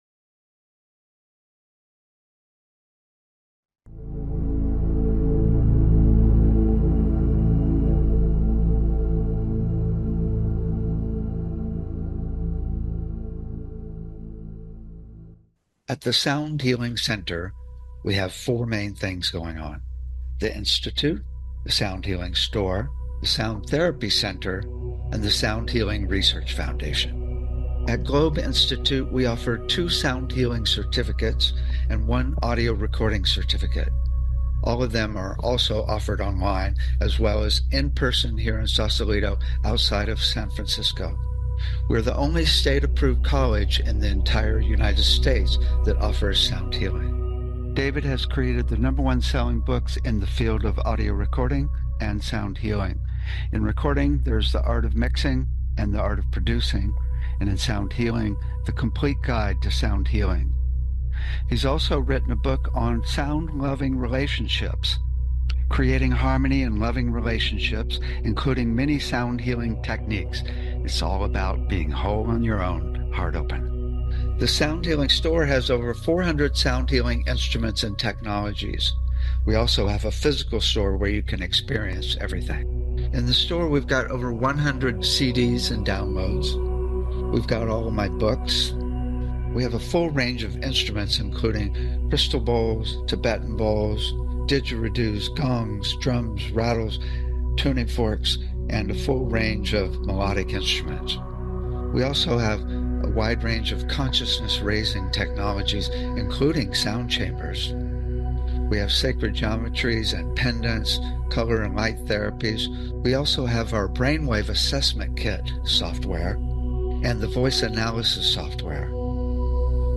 Talk Show Episode, Audio Podcast, Sound Healing and Immersive Sound Baths on , show guests , about Sound Baths,Sound Healing,Sound Bath Demonstrations,frame drum,light language transmission,Sanskrit chant,Aham Prema,frequency through the body, categorized as Health & Lifestyle,Energy Healing,Sound Healing,Emotional Health and Freedom,Science,Self Help,Society and Culture,Spiritual,Meditation